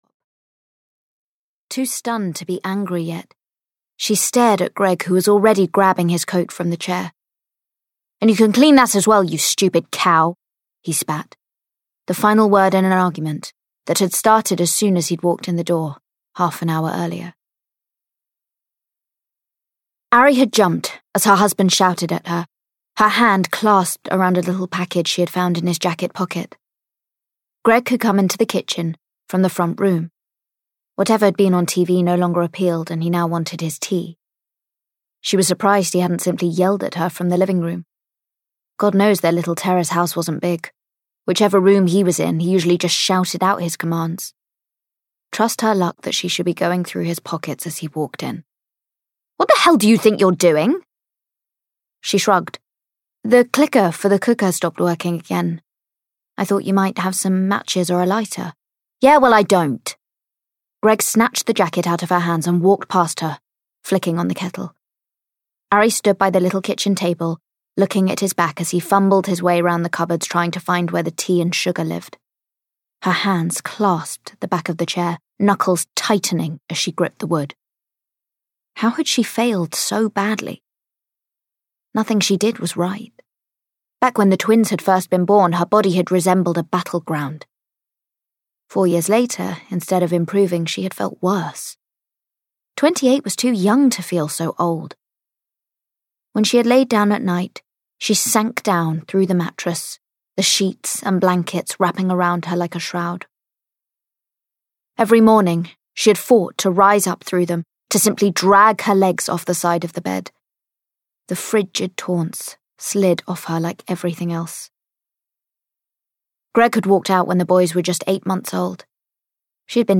A New Life for Ariana Byrne (EN) audiokniha
Ukázka z knihy